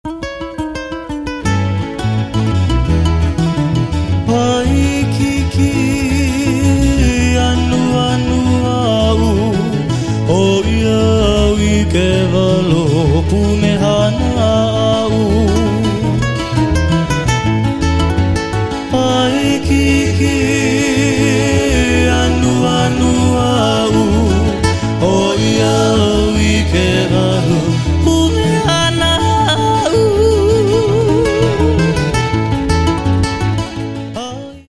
somewhat nostalgic Hawaiian sound
Genre: Traditional Hawaiian.